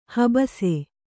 ♪ habasi